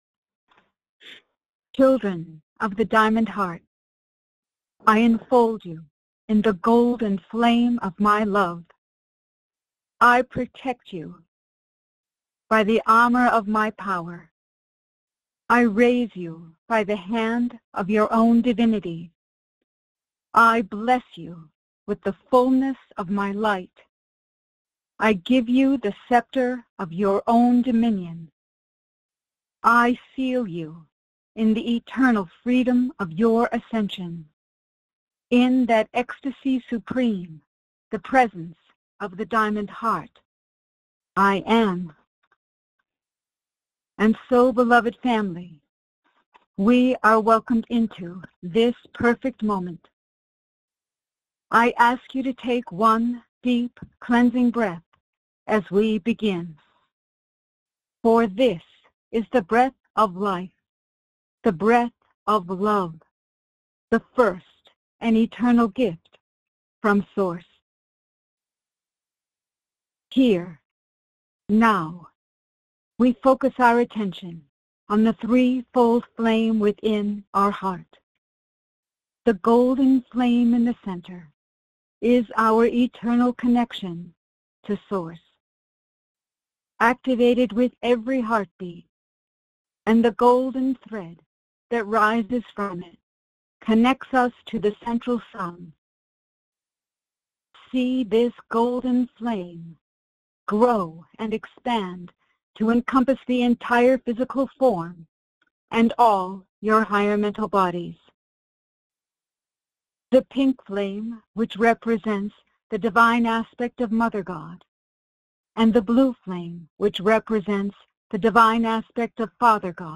Audio Recording Meditation
Channeling – Minute (19:01) Transcription coming soon ….. Questions & Answers – Minute (27:09) Transcription coming soon …..